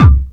Kicks
WU_BD_268.wav